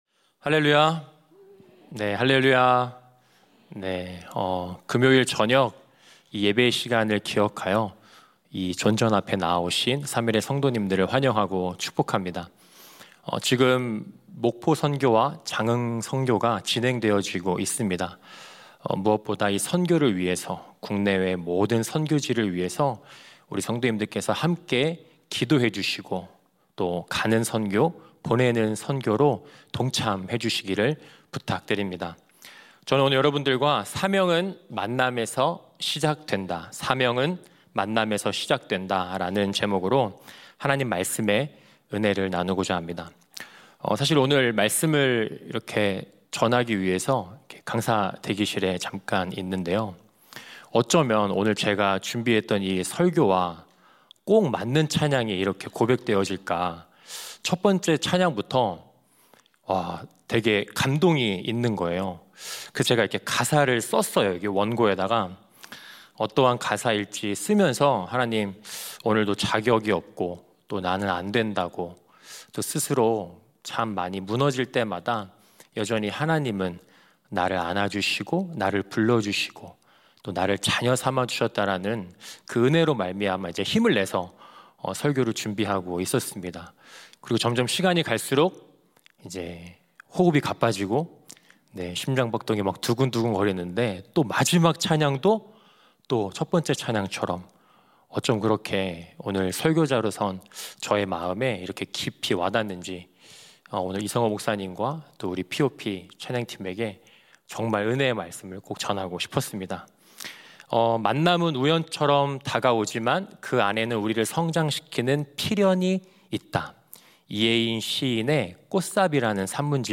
새벽예배